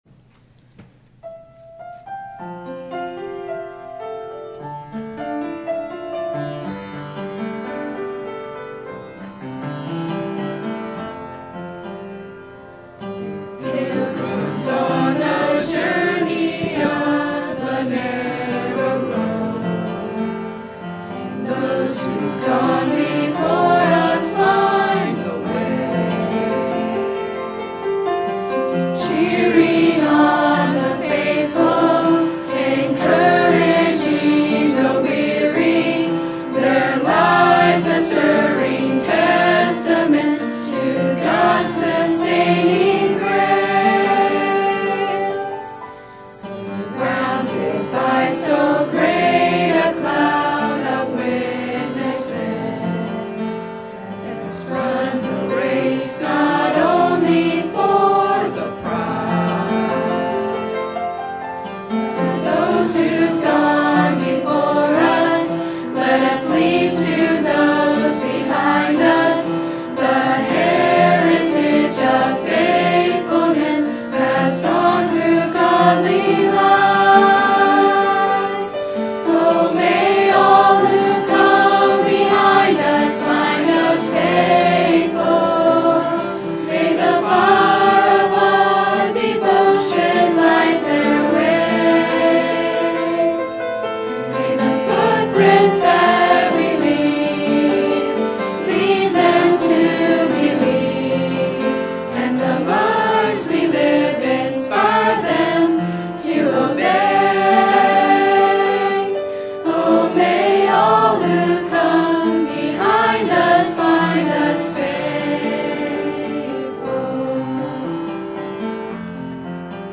Sermon Topic: Youth Conference Sermon Type: Special Sermon Audio: Sermon download: Download (25.25 MB) Sermon Tags: 2 Kings Spirit Future Youth